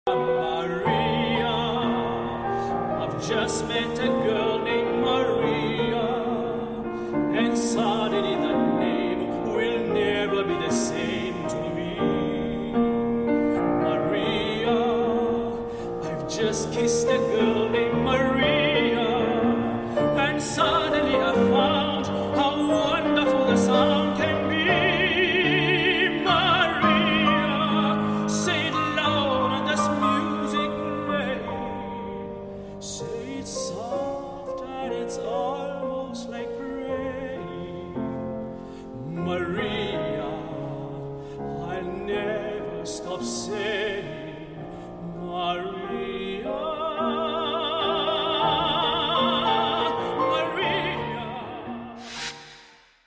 Am Klavier im Bergson Kunstkraftwerk München